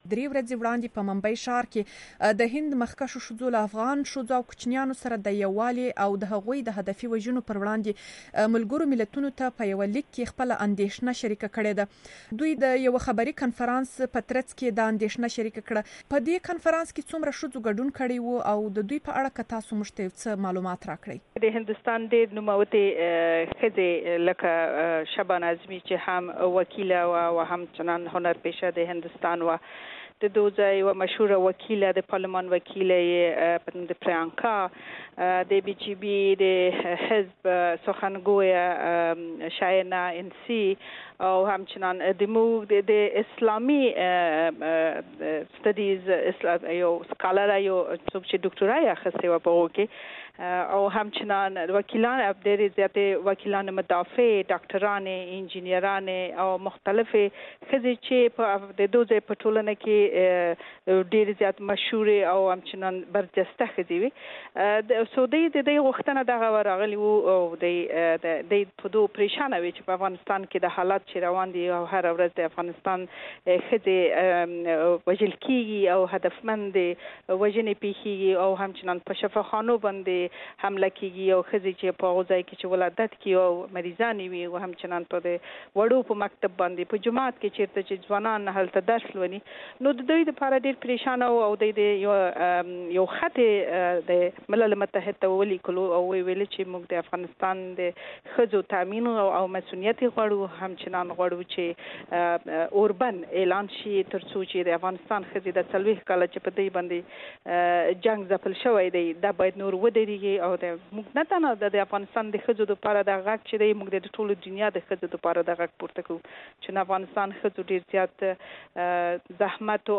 د افغانستان له جنرال قونسلې ذکیه وردک سره مرکه